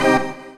ORGAN-16.wav